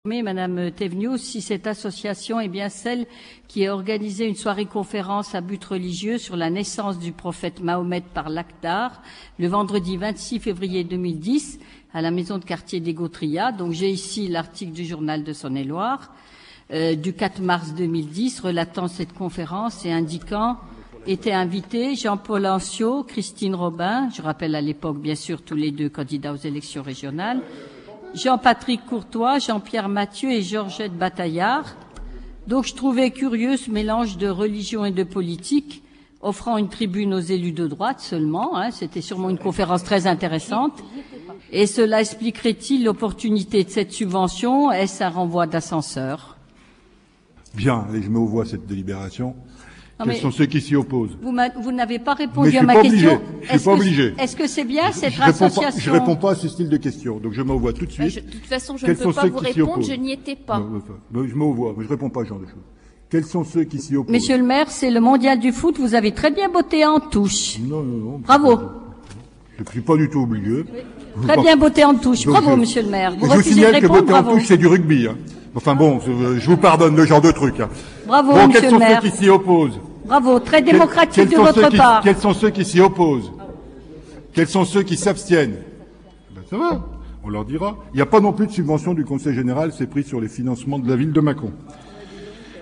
Cliquez sur le lien et Ecoutez les interventions de l’opposition sur le versement d’une subvention pour « retour d’ascenseur » électoral » (